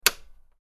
Plastic Button Click Sound – UI Tap Effect
Description: Plastic button click sound – UI tap effect.
Improve user interface feedback with a clean plastic click.
Genres: Sound Effects
Plastic-button-click-sound-ui-tap-effect.mp3